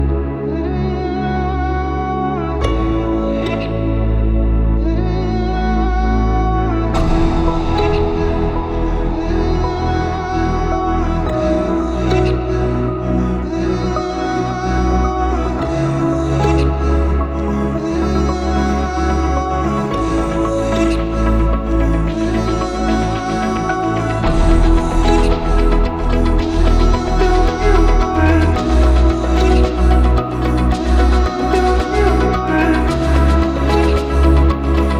# Dance